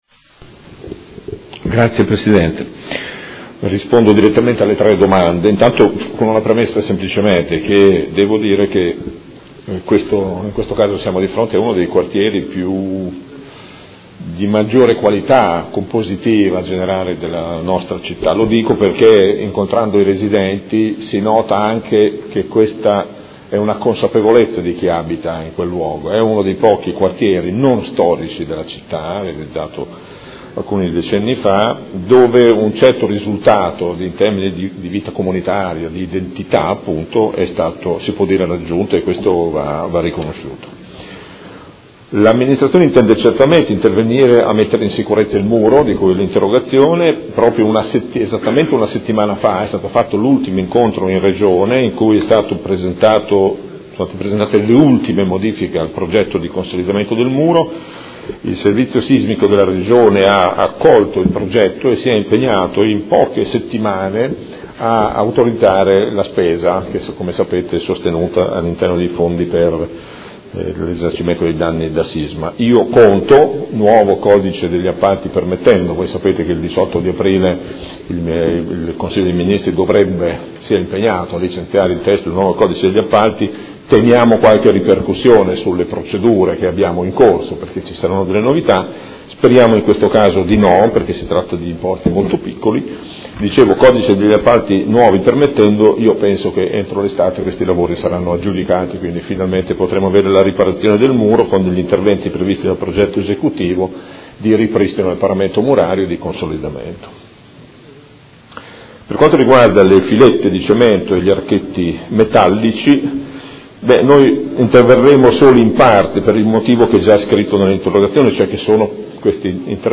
Seduta del 31/03/2016. Interrogazione del Consigliere Stella (P.D.) avente per oggetto: Opere di ristrutturazione e riqualificazione del Comparto Torrenova. Risponde l'Assessore